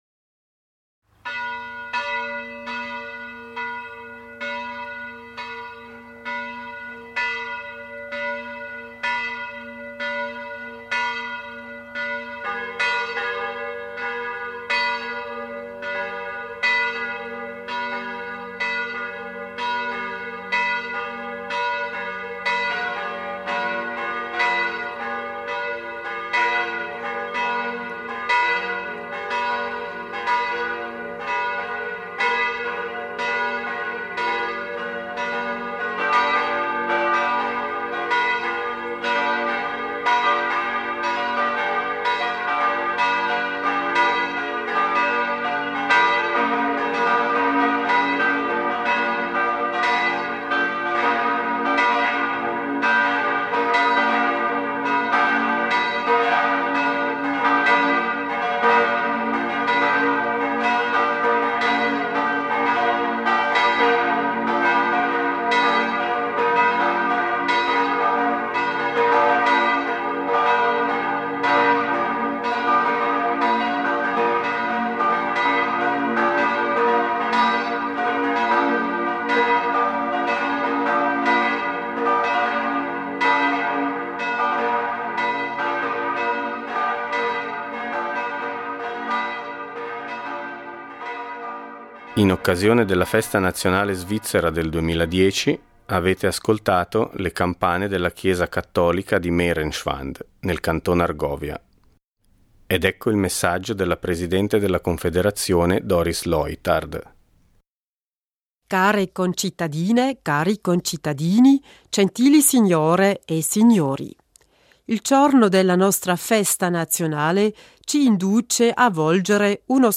Discorso della presidente della Confederazione Doris Leuthard in occasione della Festa nazionale del 1. agosto.